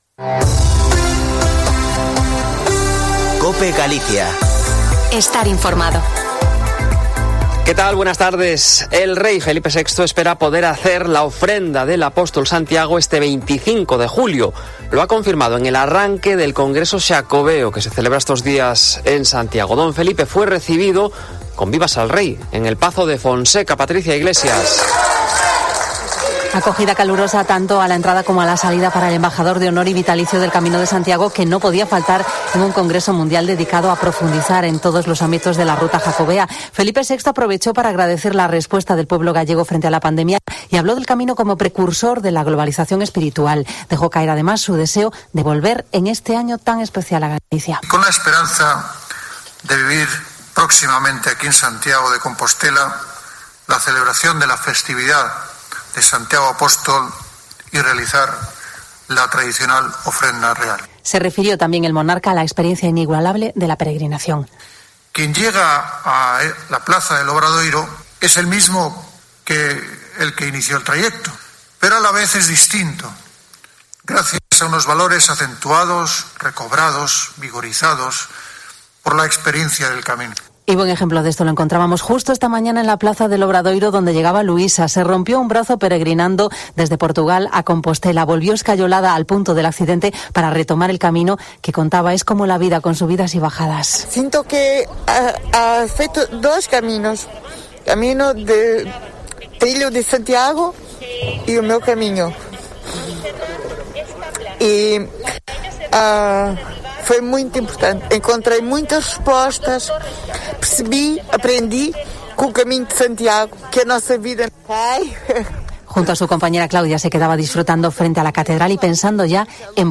Informativo Mediodia en Cope Galicia 03/06/2021. De 14.48 a 14.58h